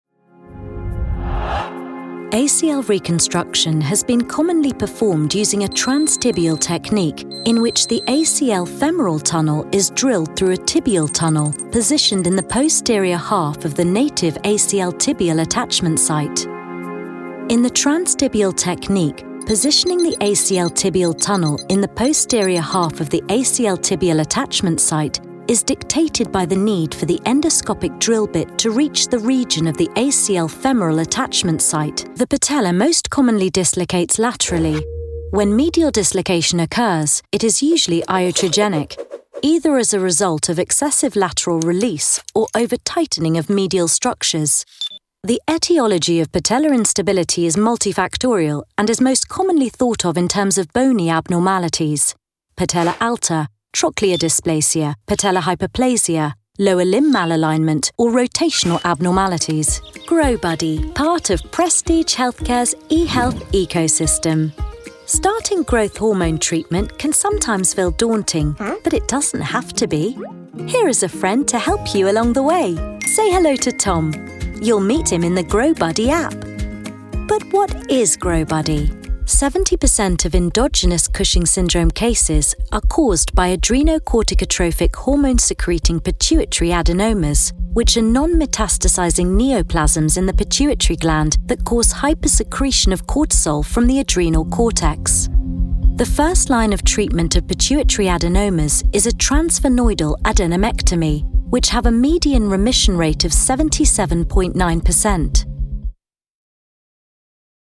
English (British)
Commercial, Reliable, Friendly, Warm, Corporate
Corporate